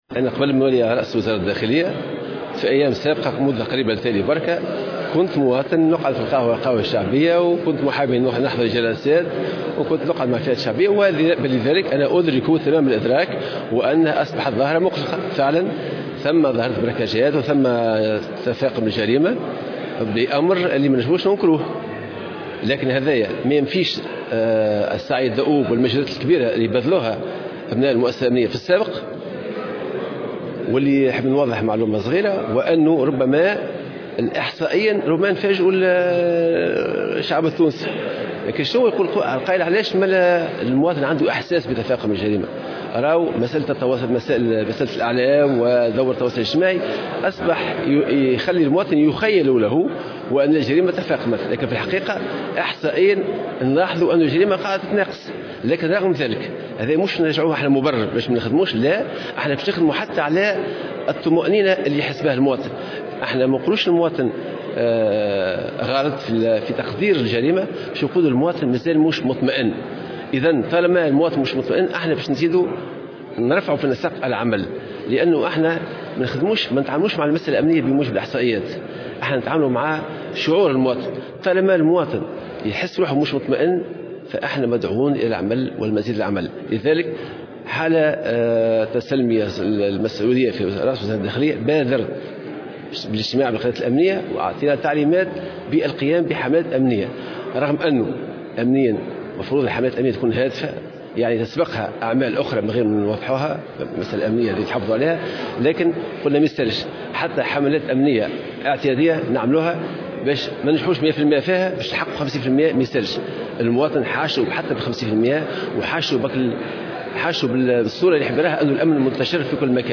في المقابل، أكد شرف الدين، في تصريح لمراسل الجوهرة أف أم، على هامش ندوة الولاة، اليوم السبت، أن الوزارة ستعمل على رفع نسق عملها في مقاومة هذه الظاهرة التي تقلق المواطنين، على اعتبار وأن الوزارة لا تتعامل مع المسألة الأمنية طبقا للإحصائيات، وإنما طبقا لشعور المواطن الذي لا يزال يعيش حالة من القلق وعدم الاطمئنان.